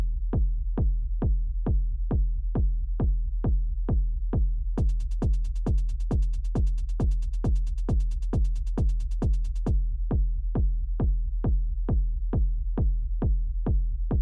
Those are 909 hats, 909s go really deep into low mids…
for what its worth, I don’t hear it in the first example, but I listened to the second example 3 times in headphones and I now think I know what you’re talking about. it does sound a bit like a long compressor release stage.